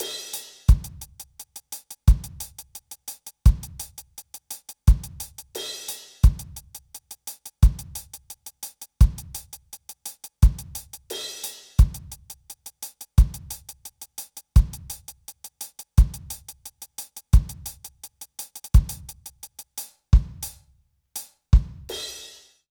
British REGGAE Loop 088BPM (NO KICK).wav